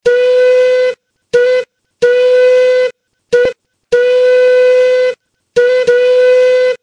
Recorded Whistles for Live Steam Locomotives
whistles_fowler.mp3